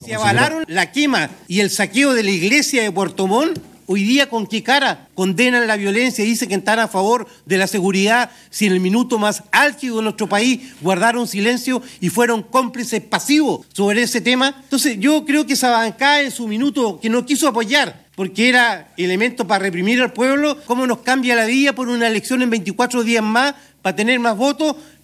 Y en la misma línea, el consejero también RN Alexis Casanova, sosteniendo que “en el punto más álgida del país, algunos fueron cómplices”.